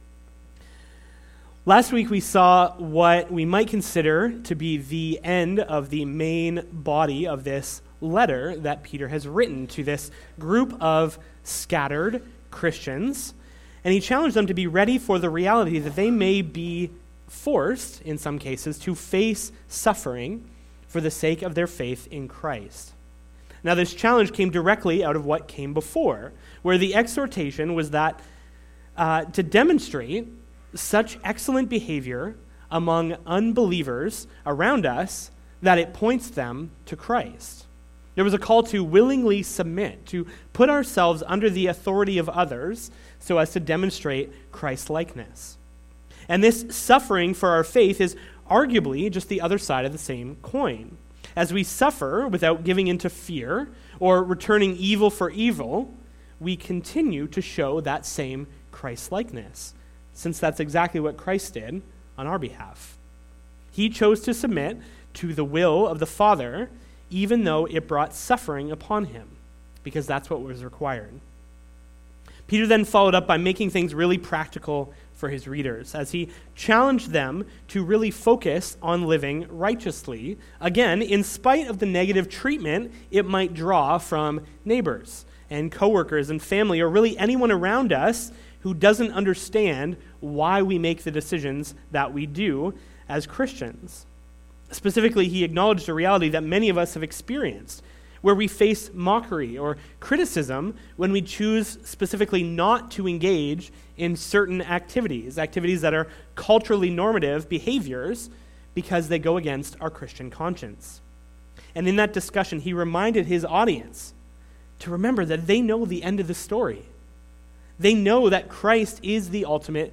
Sermon Audio and Video